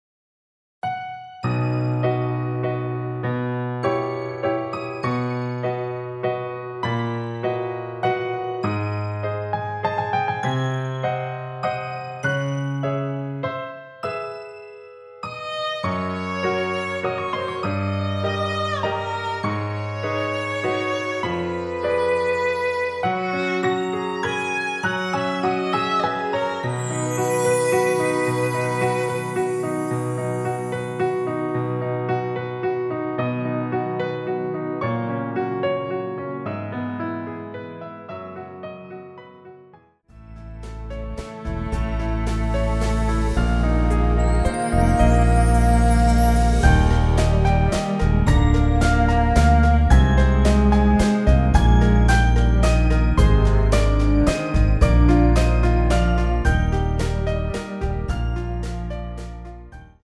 남자키에서 +2 더올린 MR입니다.
원키에서(-7)내린 MR입니다.
앞부분30초, 뒷부분30초씩 편집해서 올려 드리고 있습니다.
중간에 음이 끈어지고 다시 나오는 이유는